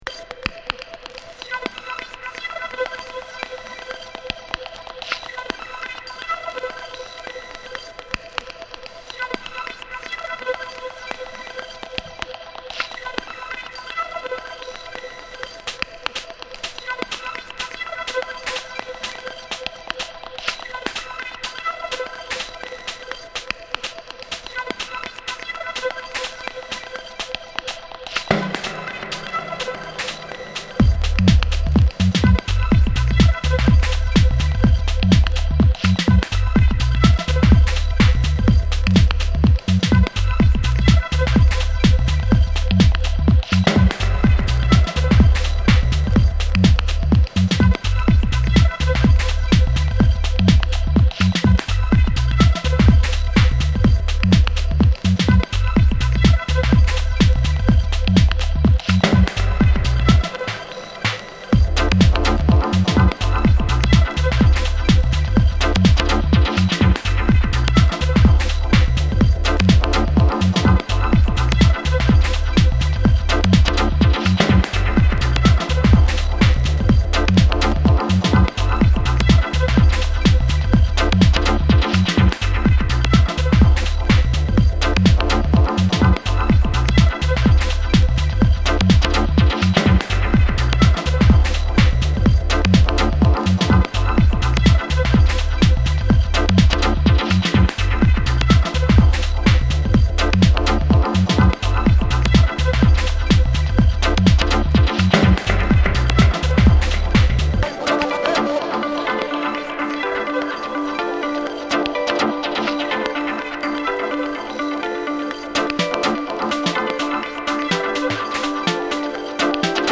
テクノ、ハウス